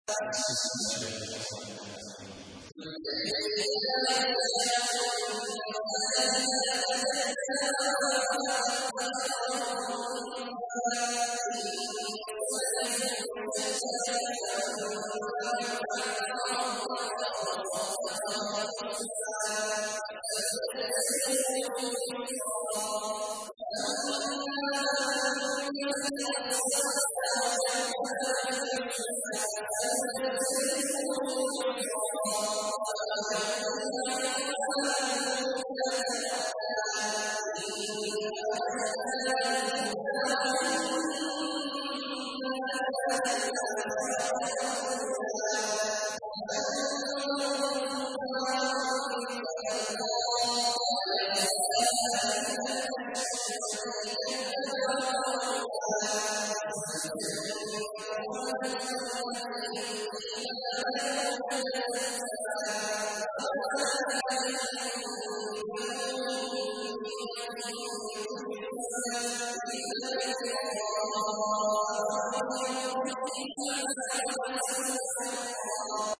تحميل : 92. سورة الليل / القارئ عبد الله عواد الجهني / القرآن الكريم / موقع يا حسين